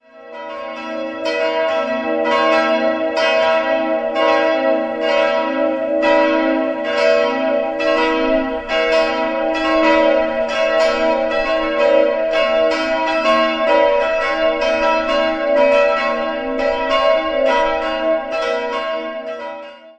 Innenansicht wird noch ergänzt. 3-stimmiges Gloria-Geläut: h'-cis''-e'' D ie Glocken wurden 1960 von der Gießerei Bachert in Karlsruhe gegossen.